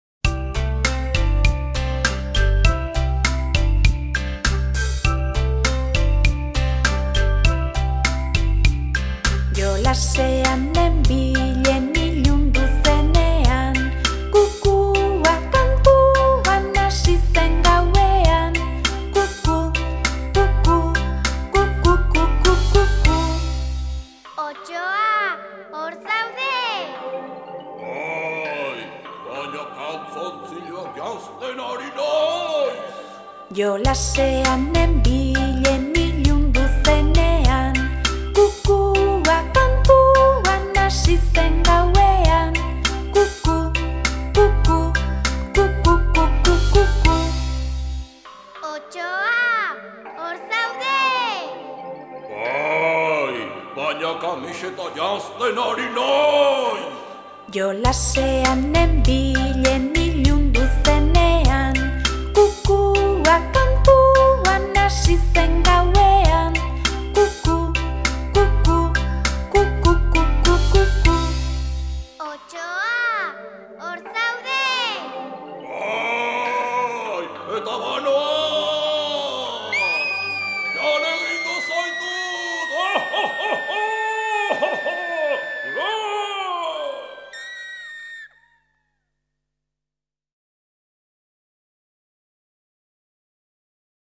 This series of Basque songs for kids was compiled by Basque newspaper EGUNKARIA.